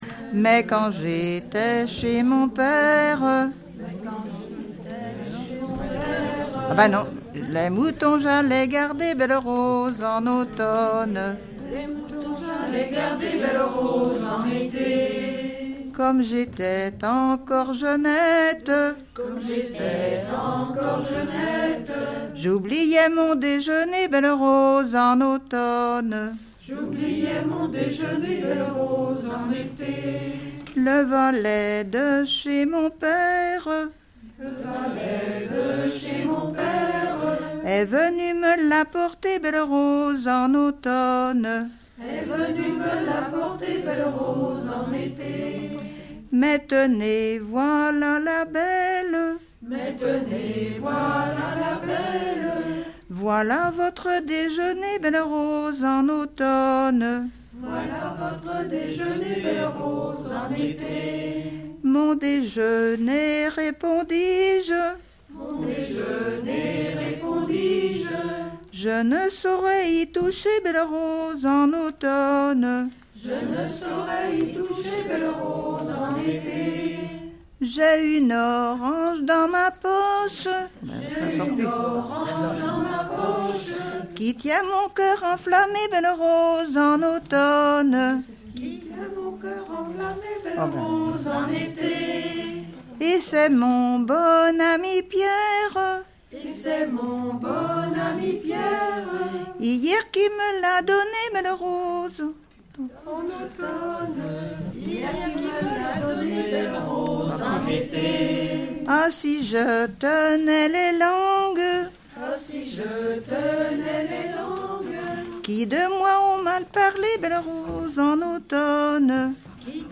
Localisation Port-Saint-Père
Genre laisse
Chansons
Pièce musicale inédite